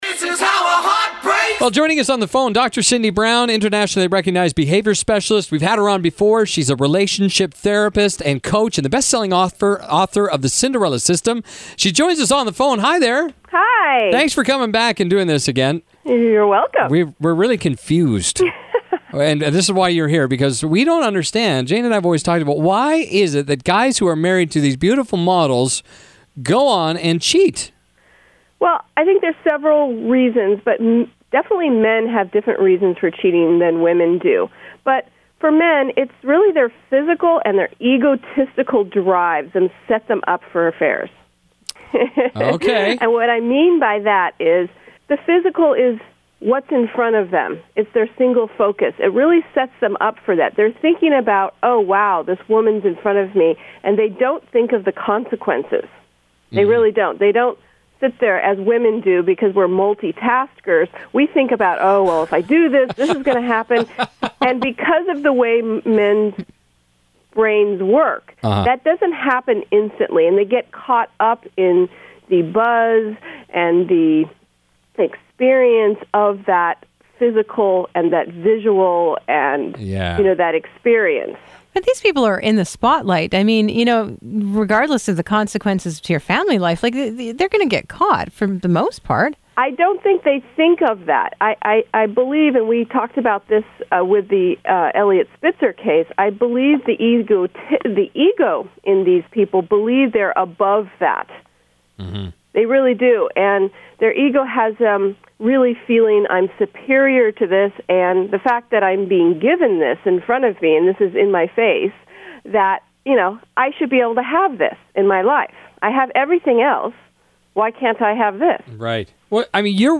Radio Interview Why Do Men Cheat